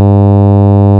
Let's compare a signal before and after filtering:
s = sawtooth(2 * np.pi * f * n / N) # note: sawtooth wave
less10sawbutterfilt.wav